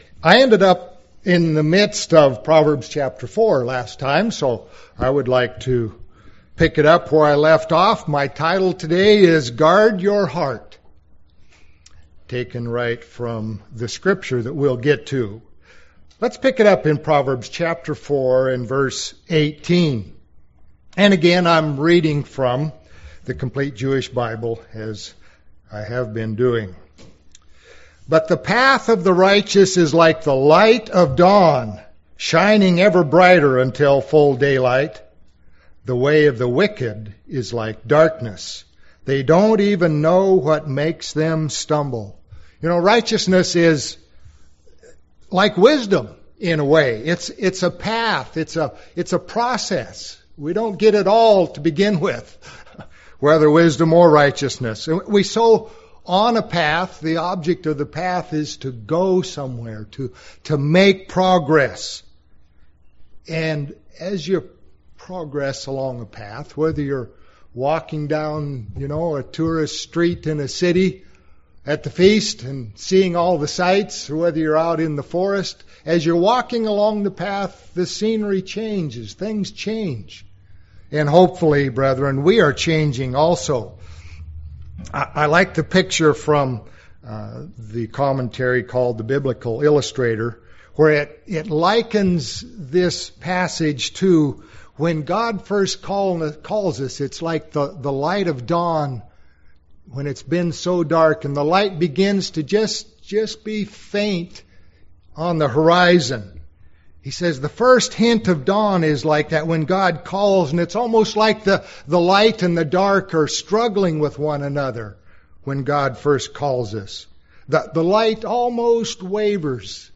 Sermons
Given in Medford, OR